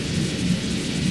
snd_paper_rumble.ogg